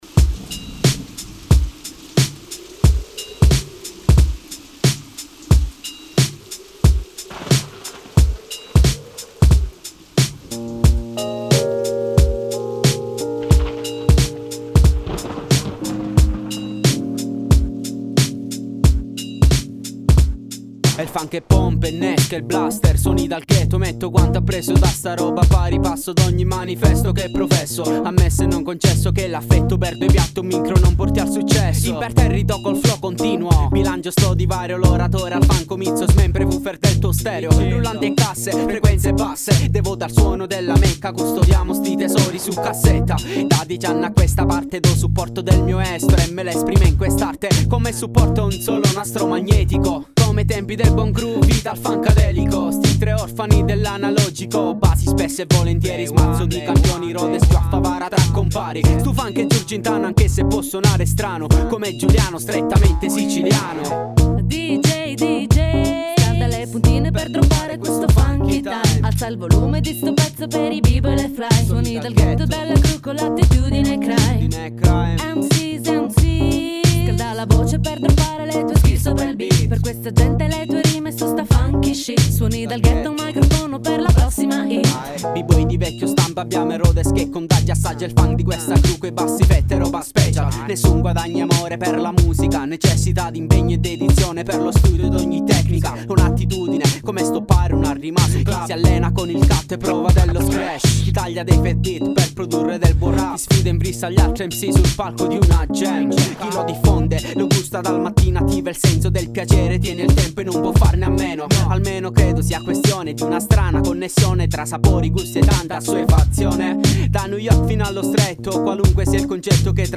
Luogo esecuzionearagona caldare - agrigento
Rhodes